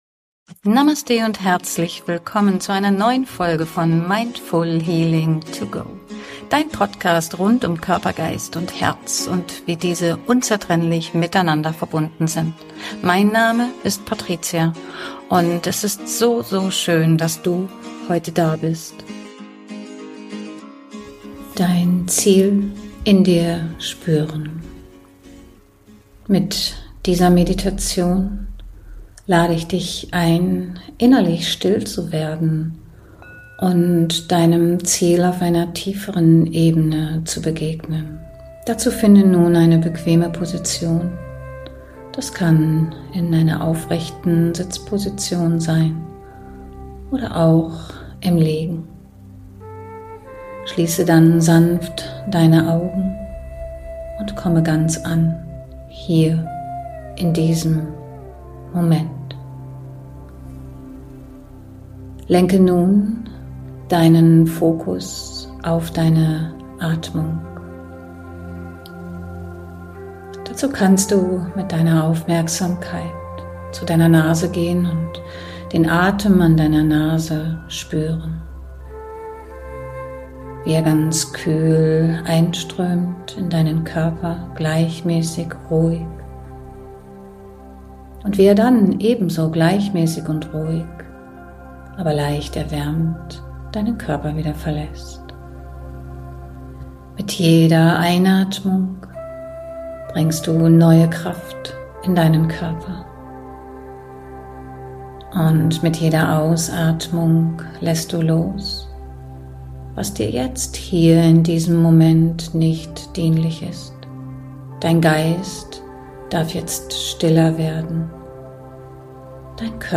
einer geführten Meditation einladen. Sie soll dir dabei helfen